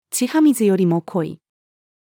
血は水よりも濃い-female.mp3